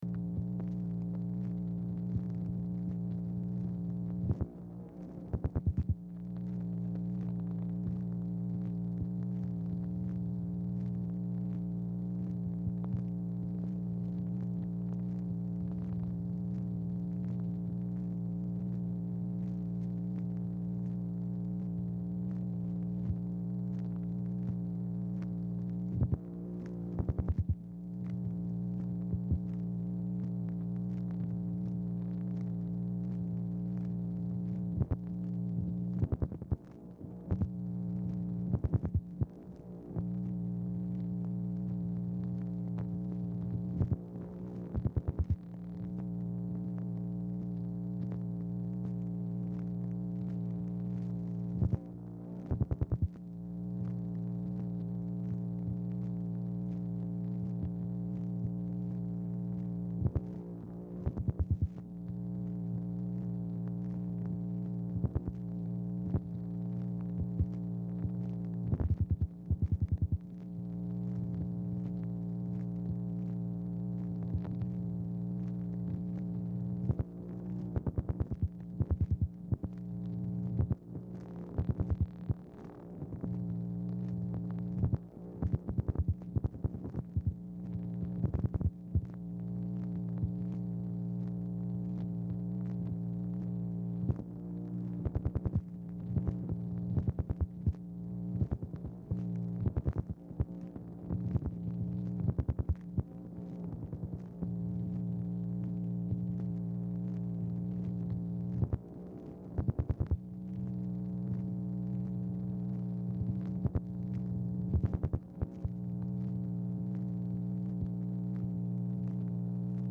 Telephone conversation # 4204, sound recording, MACHINE NOISE, 7/10/1964, time unknown | Discover LBJ
Format Dictation belt